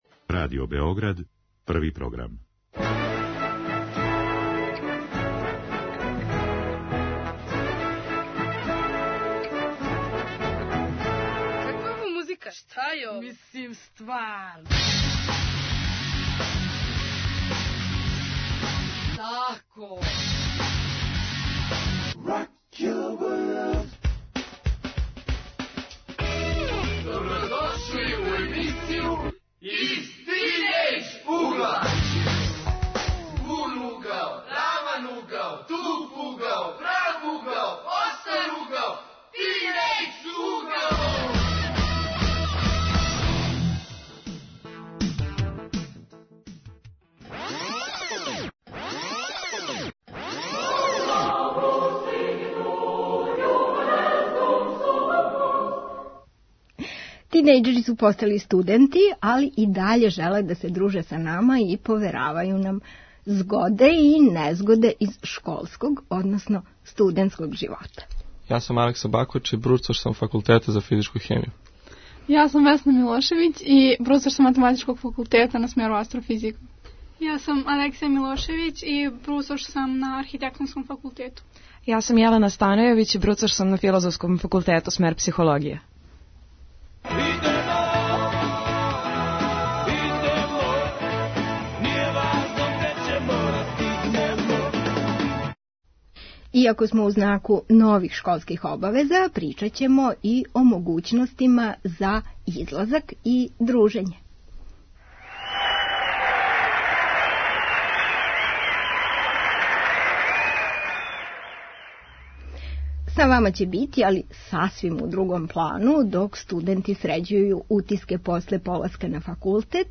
Нису више тинејџери, сад су бруцоши, али окупиће се у емисији Из тинејџ угла да проћаскају о свом новом статусу и новој етапи школовања.